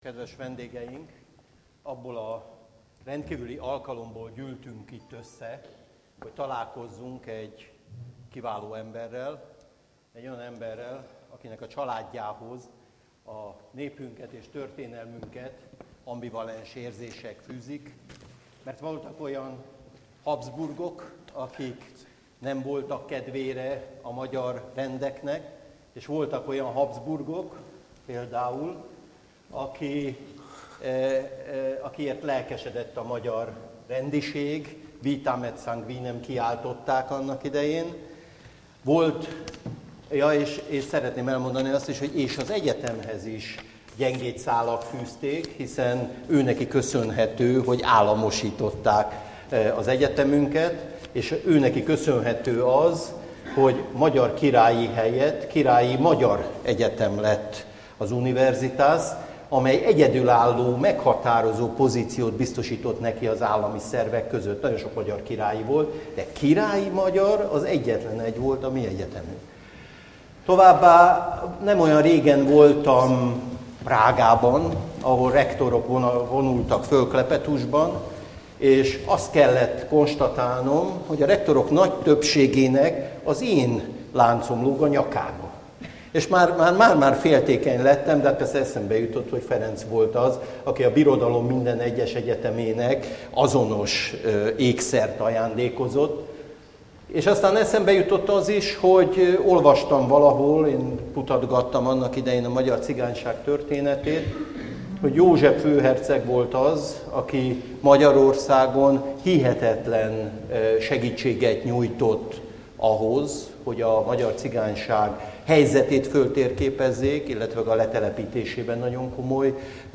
A beszélgetés teljes egészében letölthet� honlapunkról az alábbi linkr�l (a file mérete körülbelül 55 megabyte)
Az esemény helyszíne és id�pontja: ELTE Állam- és Jogtudományi Kar Aula Magna, 2013. március 13. 18:00 Kovács András Péter , mint humorista nevével bizonyára sokan találkoztatok már.